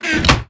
chestclosed.ogg